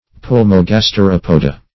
Search Result for " pulmogasteropoda" : The Collaborative International Dictionary of English v.0.48: Pulmogasteropoda \Pul`mo*gas`te*rop"o*da\, n. pl.